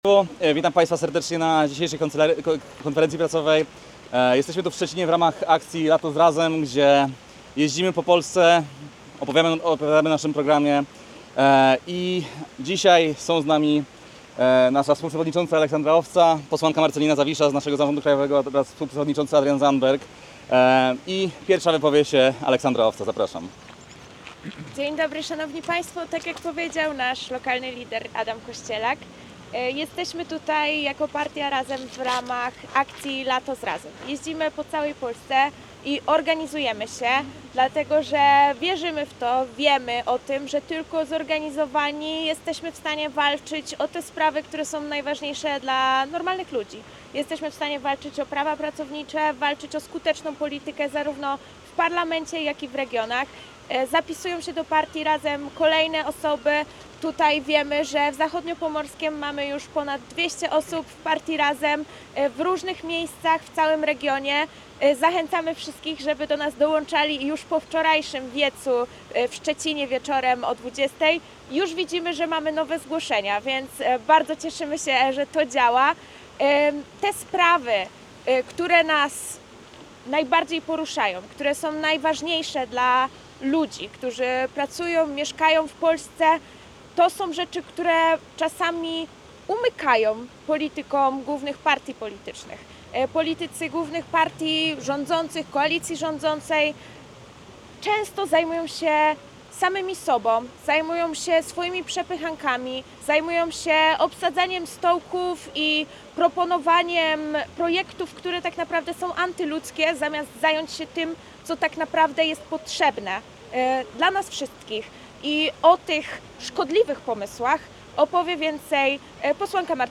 W piątek 1 sierpnia liderzy Partii Razem w ramach trasy Lato z Razem, kontynuowali wizytę w Szczecinie.